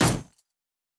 Weapon UI 14.wav